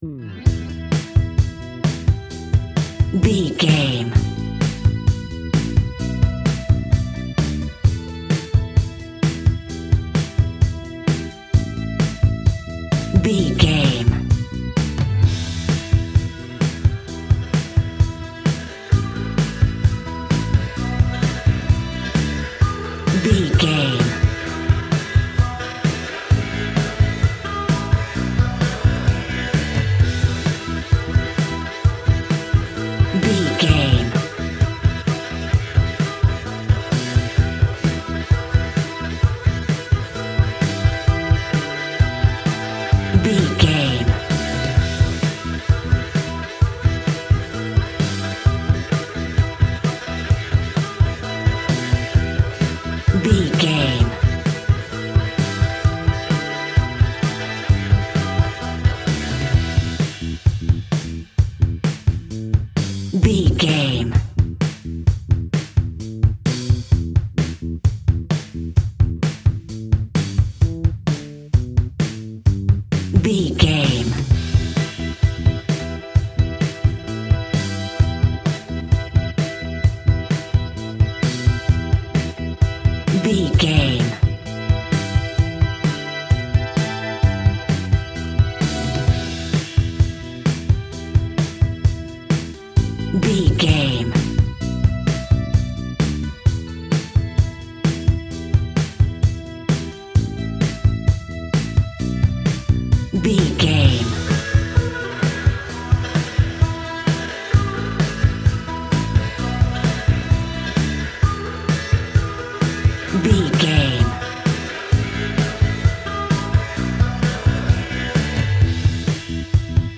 Ionian/Major
E♭
hard rock
blues rock
distortion
instrumentals